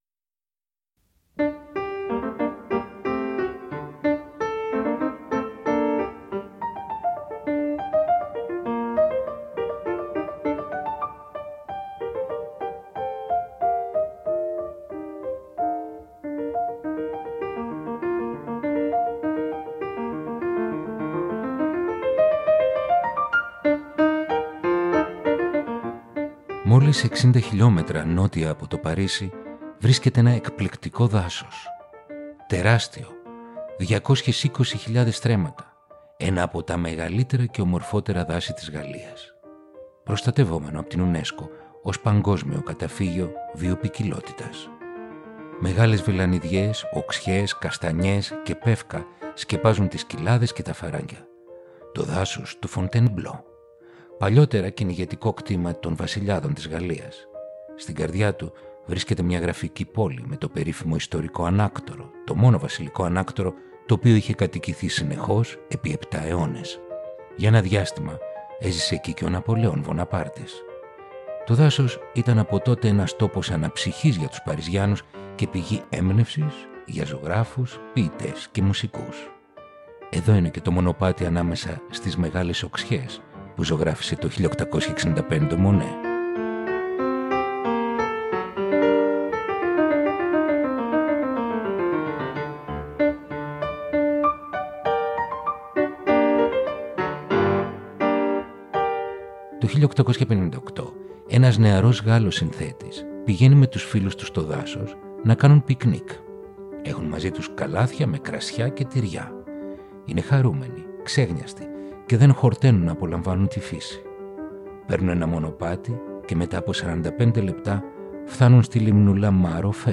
Ρομαντικά κοντσέρτα για πιάνο – Επεισόδιο 12ο